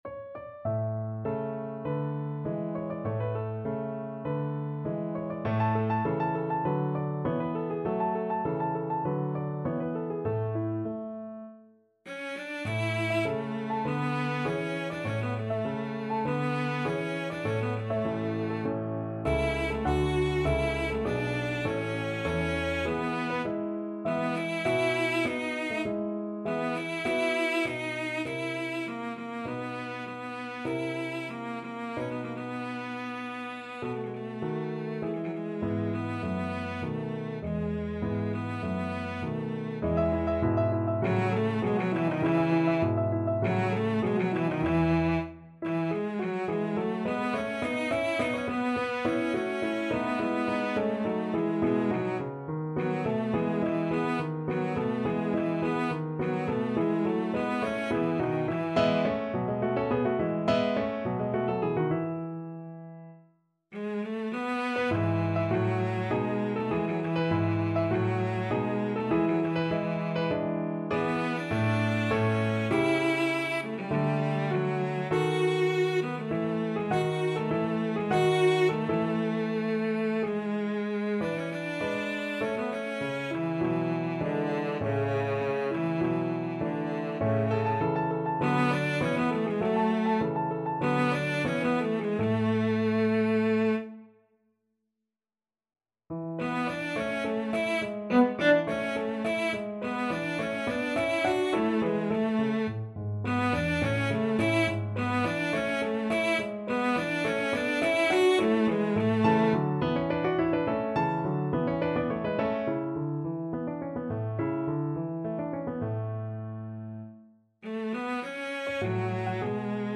Cello
A major (Sounding Pitch) (View more A major Music for Cello )
~ = 50 Larghetto
2/4 (View more 2/4 Music)
Classical (View more Classical Cello Music)